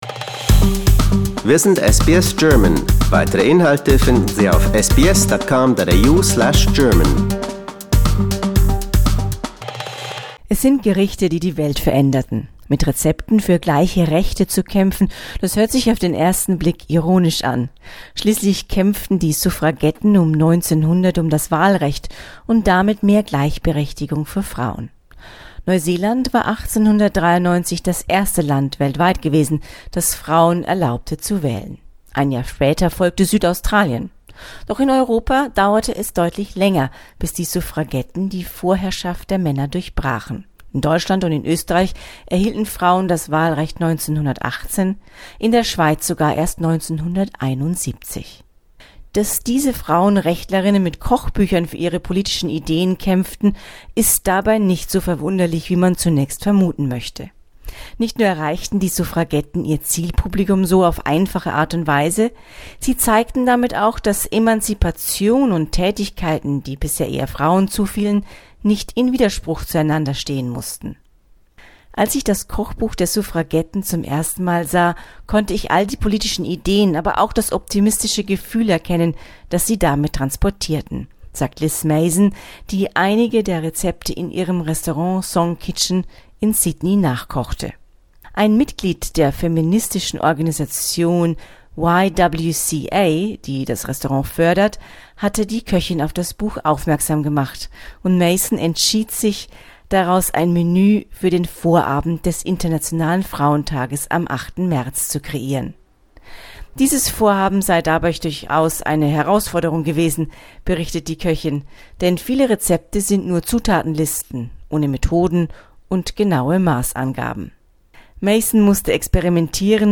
For more, listen to this quirky report.